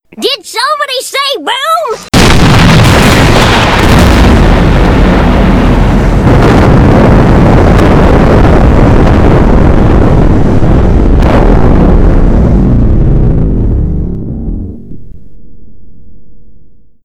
boom_edited.wav